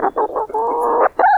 Added chicken sounds to the chickens.